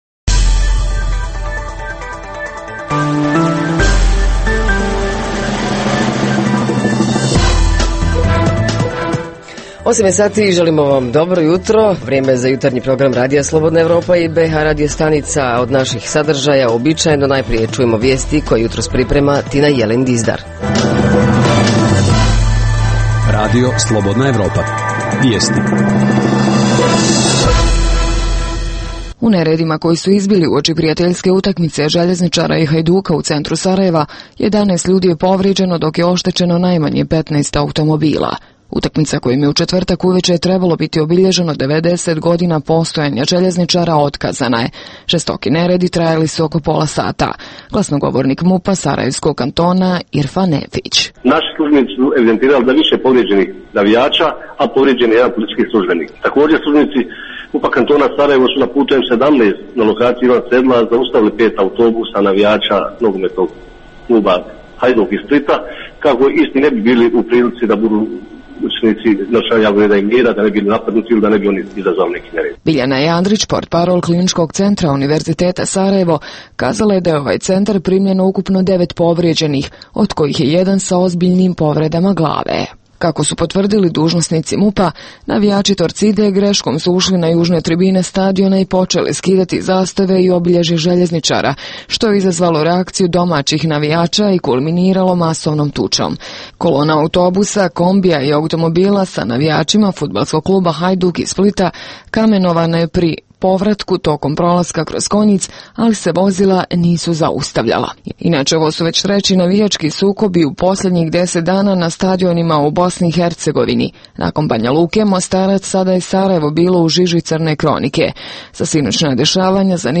Zajednička tema: Da li bi se moglo više učiniti na kulturnoj razmijeni između bh. gradova, pogotovu između gradova dva entiteta? Reporteri iz cijele BiH javljaju o najaktuelnijim događajima u njihovim sredinama.
Redovni sadržaji jutarnjeg programa za BiH su i vijesti i muzika.